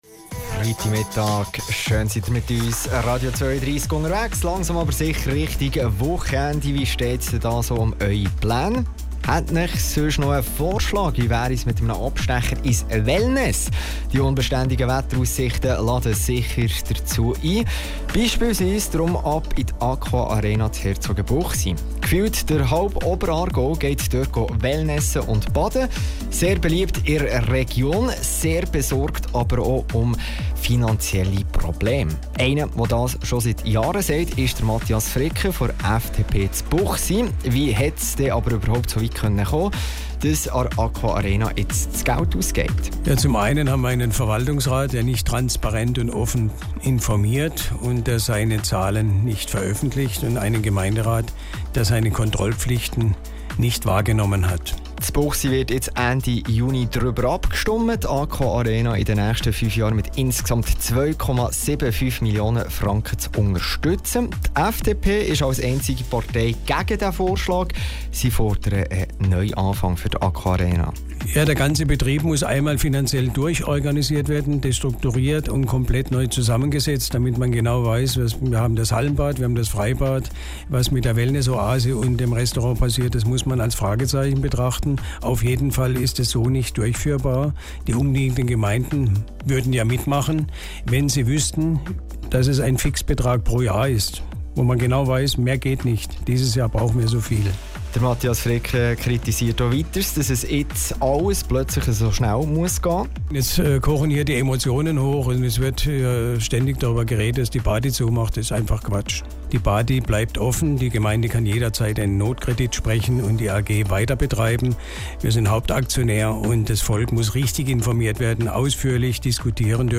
Sendebeitrag Radio 32 zur AquArenA Sport und Wellness AG